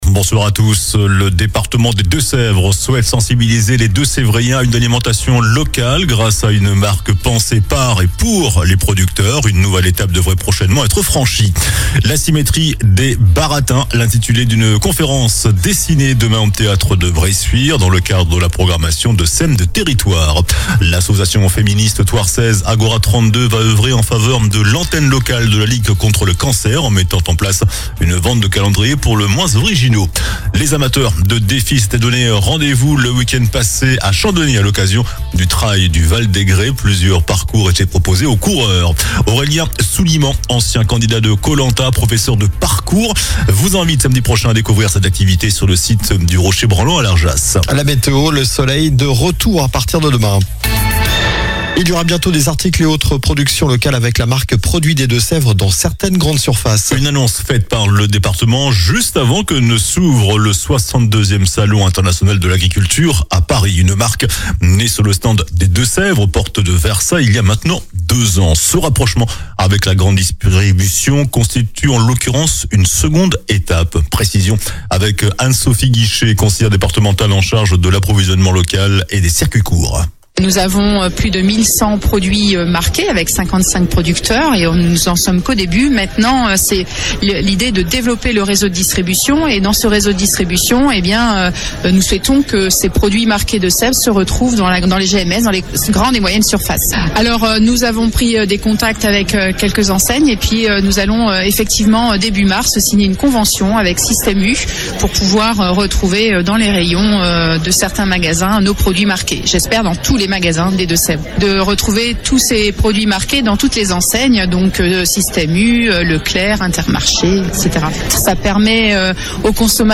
JOURNAL DU LUNDI 23 FEVRIER ( SOIR )